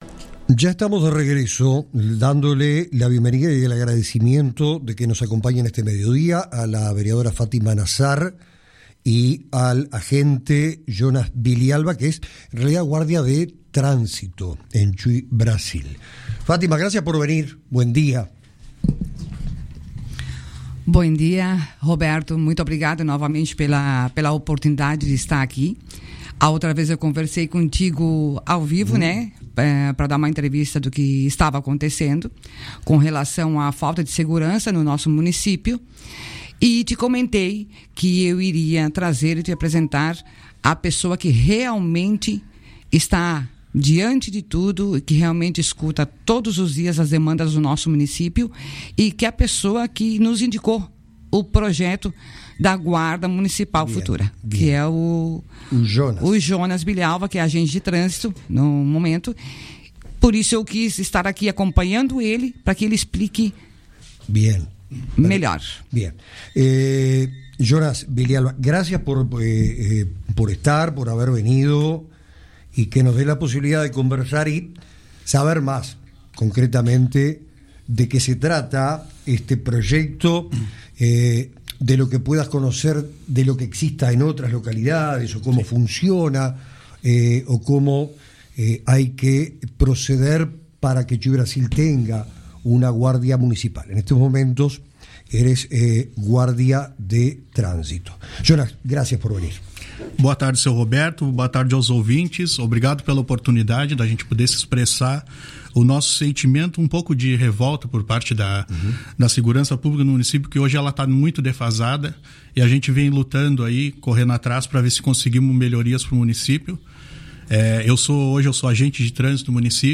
La falta de seguridad en Chuì, Brasil, fue tema central en una entrevista